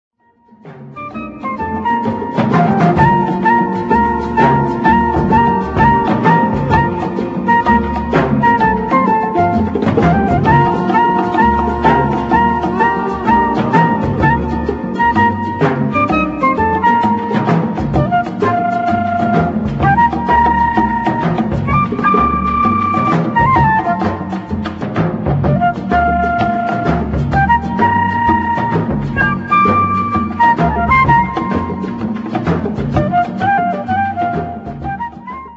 1962 funny medium instr.